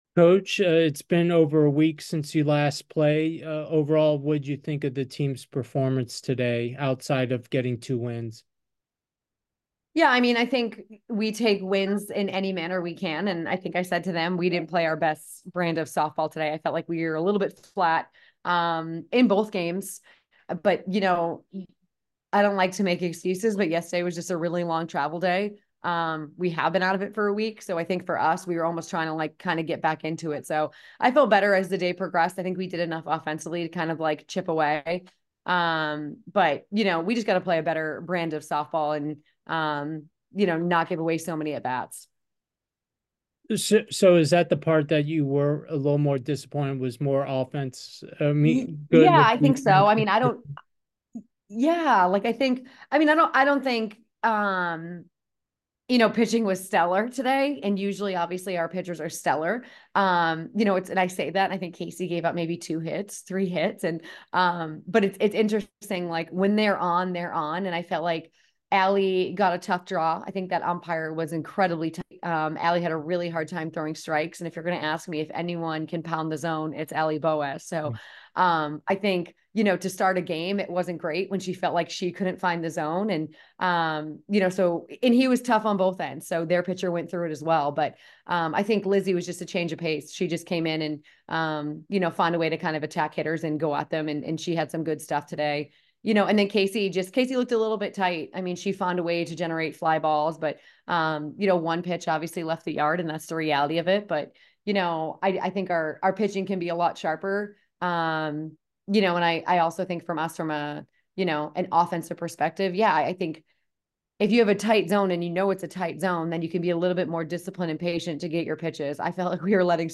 Beach Chicken Brawl Day 1 Postgame Interview